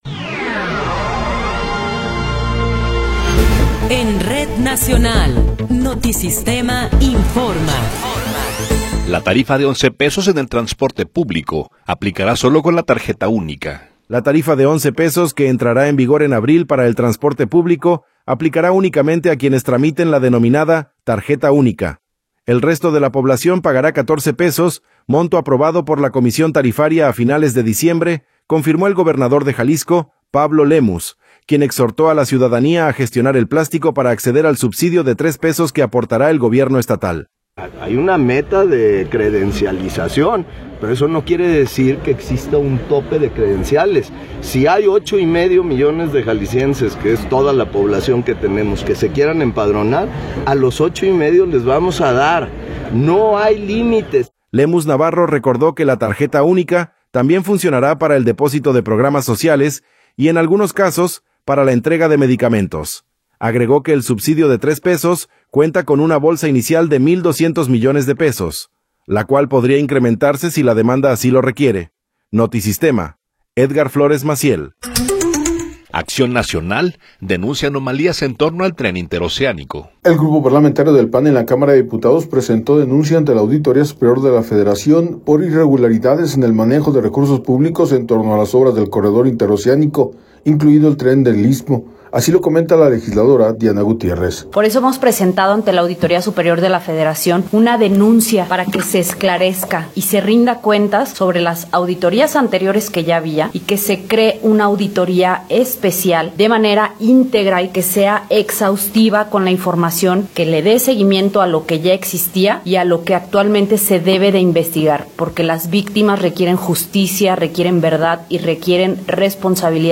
Noticiero 19 hrs. – 6 de Enero de 2026